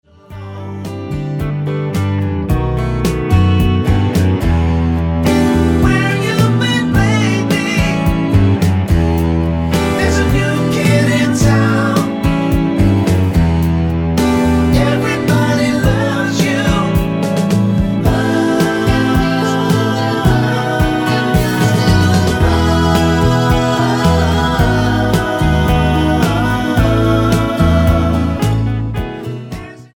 Tonart:E mit Chor